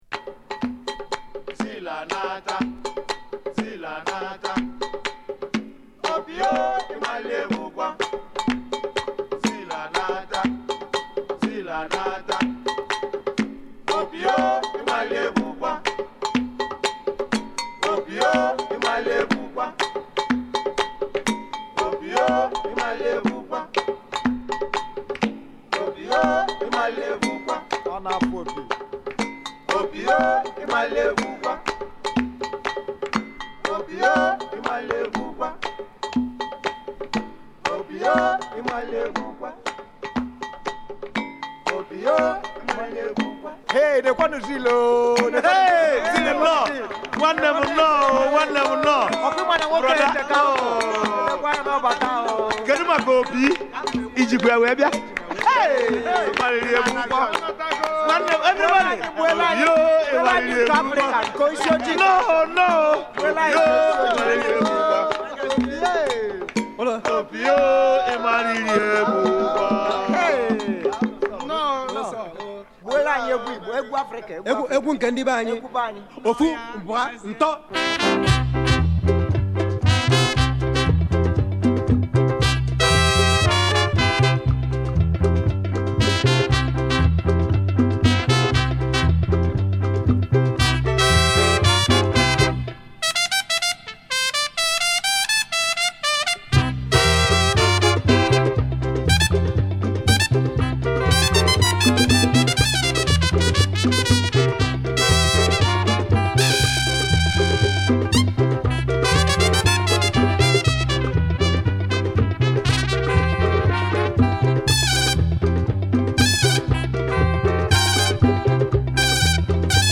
ハイライフ〜アフリカンジャズ。